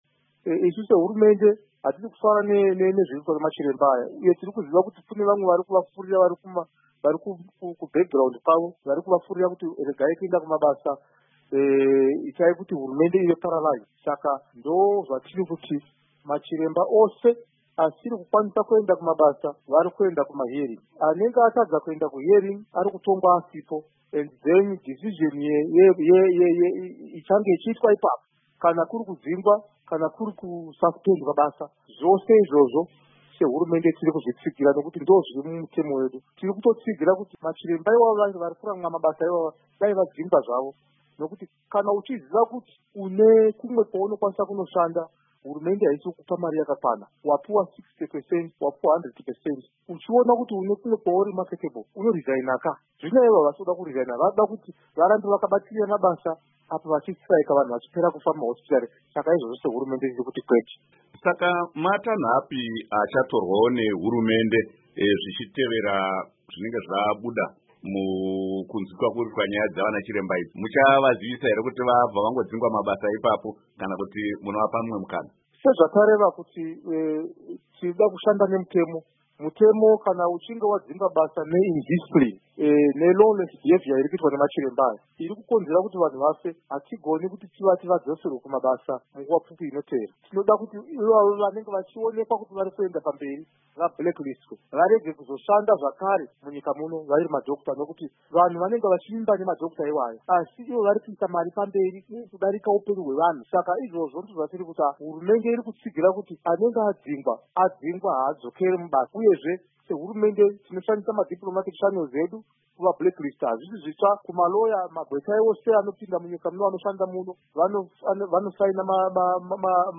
Hurukuro naVaEnergy Mutodi